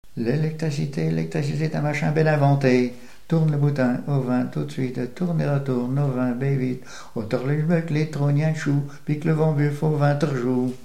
chanson en patois
Genre brève
Pièce musicale inédite